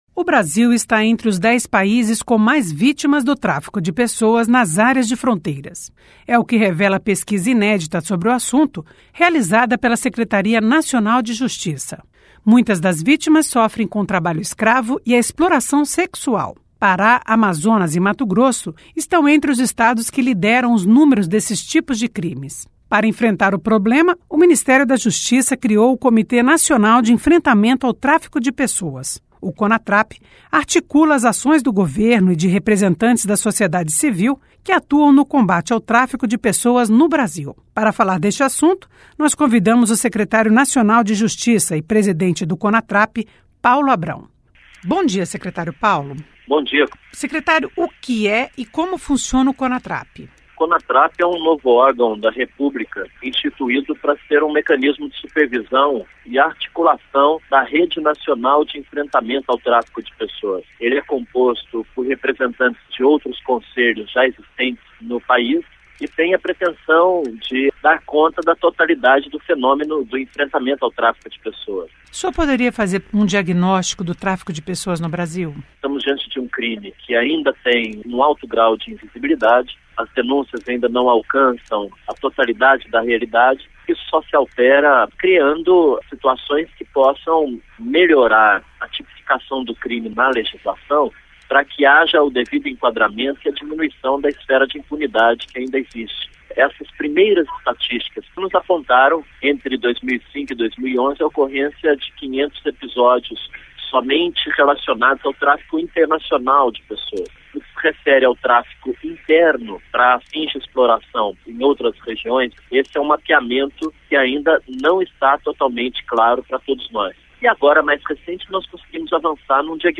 Entrevista o secretário nacional de Justiça e presidente do Conatrap, Paulo Abrão.